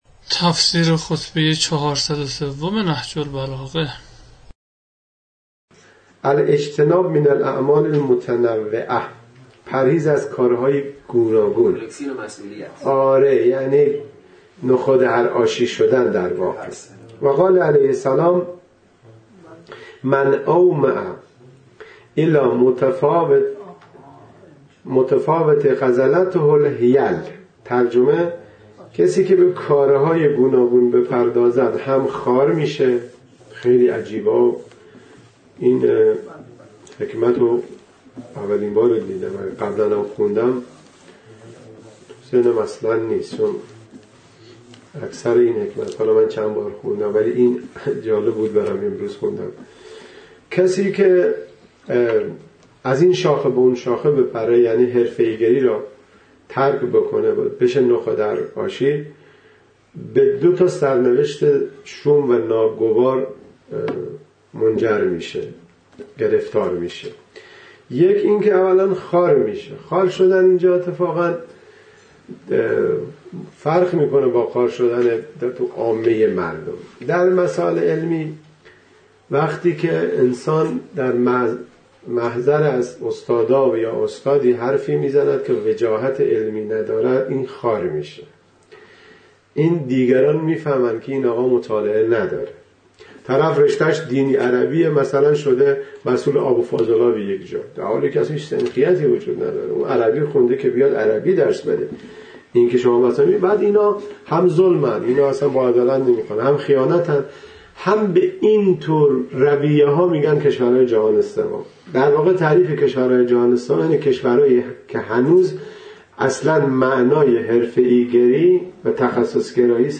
تفسیر صوتی حکمت 403 نهج البلاغه